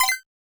Coins (16).wav